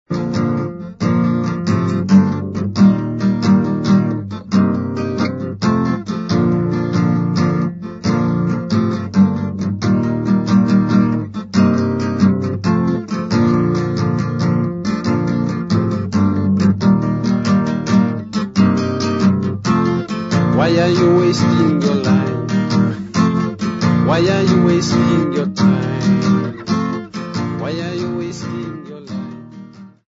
Folk songs, Xhosa
Guitar
Sub-Saharan African music
field recordings
Topical song with guitar accompaniment